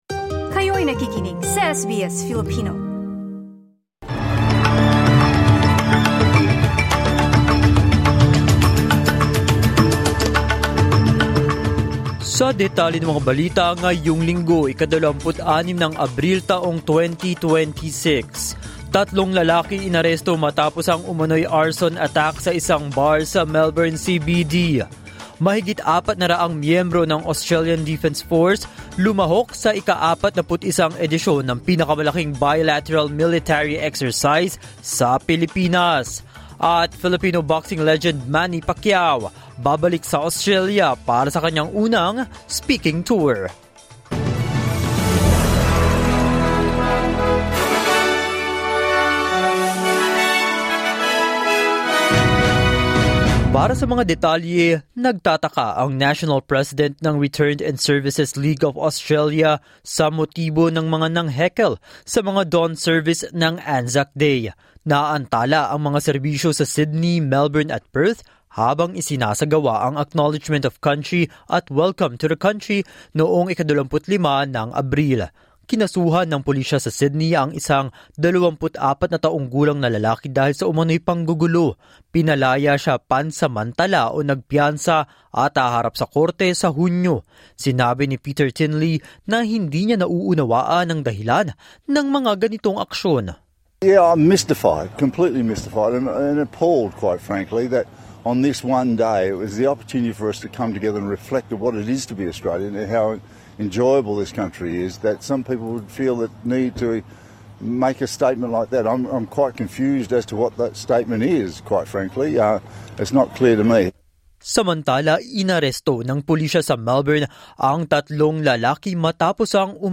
SBS News in Filipino, Sunday 26 April 2026